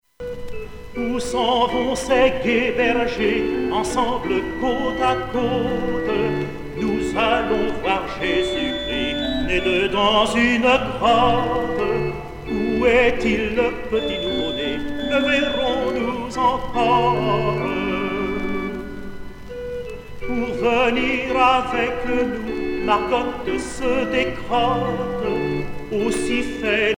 circonstance : Noël, Nativité
Genre strophique
Pièce musicale éditée